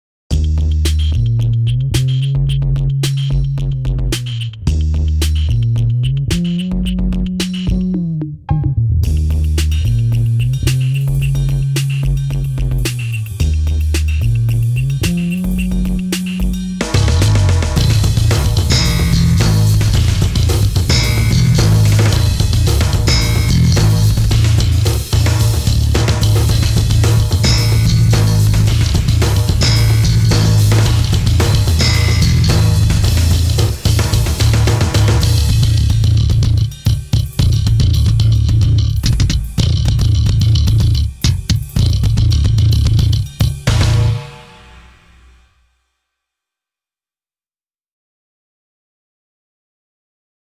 BOSS EQ-20 equalizer kytarový efekt
Zvukové zkoušky
BOSS EQ-20 - bass